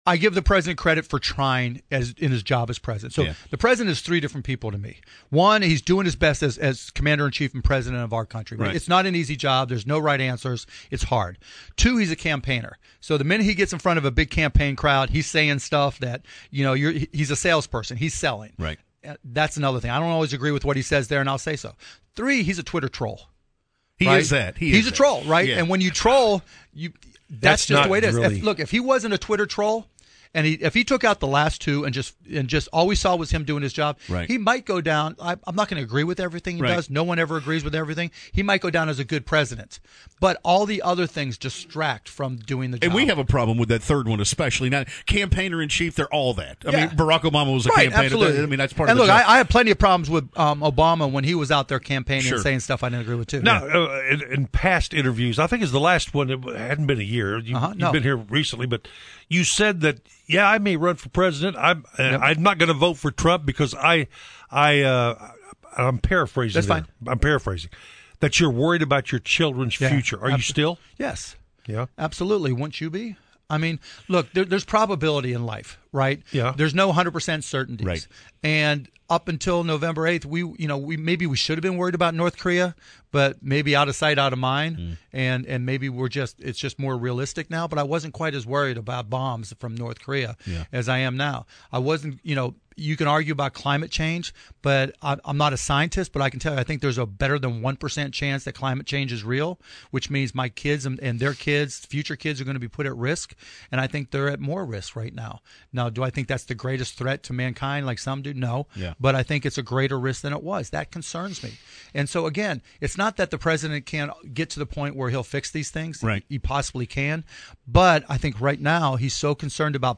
DALLAS (WBAP/KLIF News) — Dallas Mavericks’ owner Mark Cuban joined WBAP and KLIF in studio Monday morning to discuss a variety of topics, including the job President Trump is doing, whether or not he will run for President in 2020, the national anthem kneeling controversy, and of course, the 2017-2018 Mavericks season.